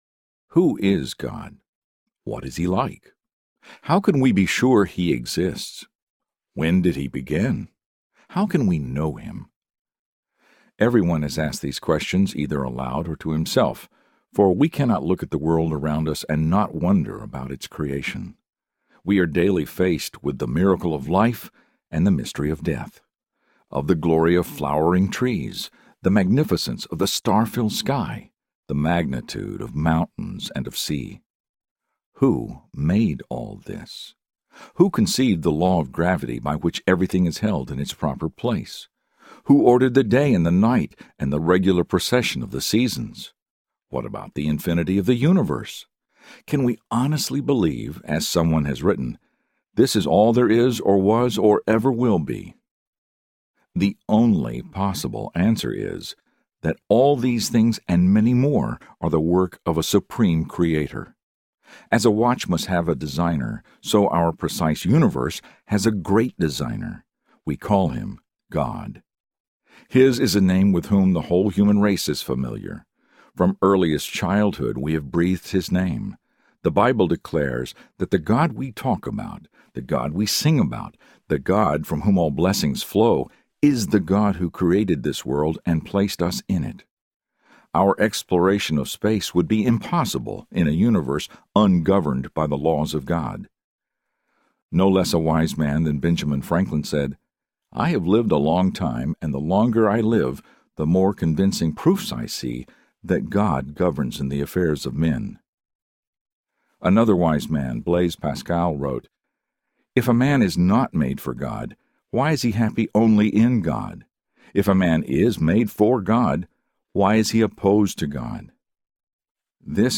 Peace with God Audiobook
Narrator
8.37 Hrs. – Unabridged